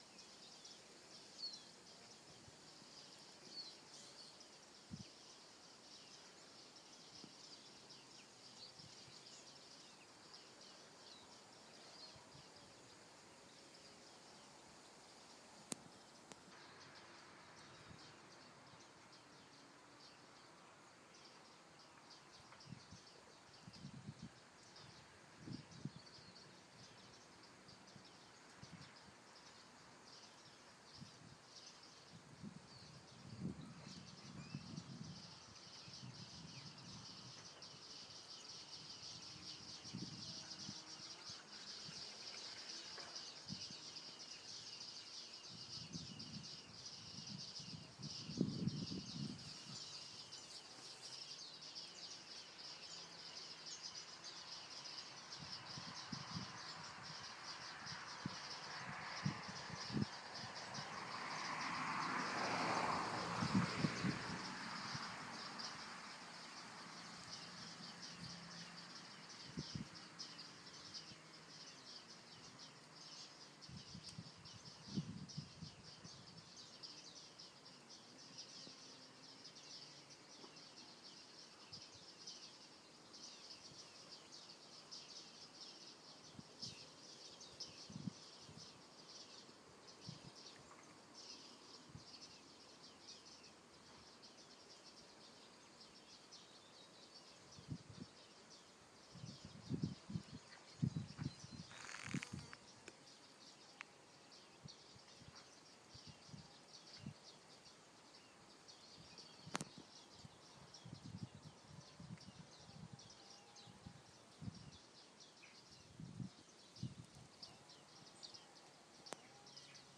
A flock of bearded tits pinging away this morning while on my 'commute' from Eastbridge to Minsmere.